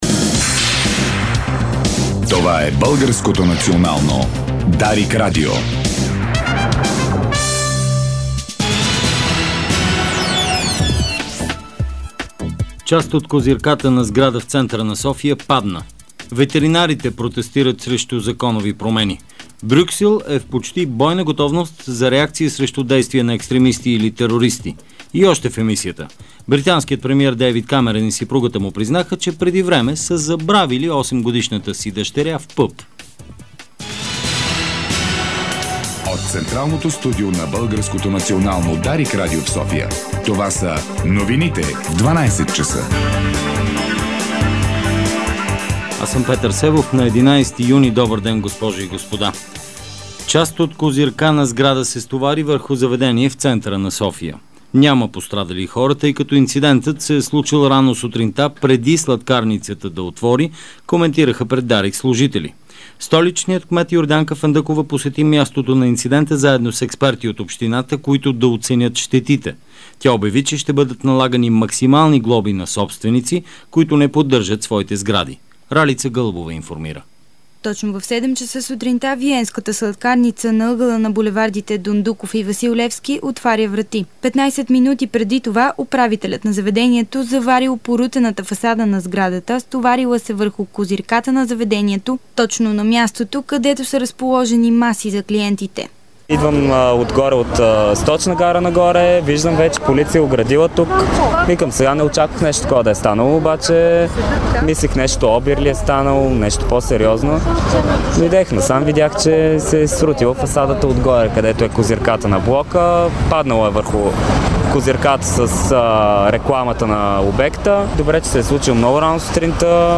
Обедна информационна емисия - 11.06.2012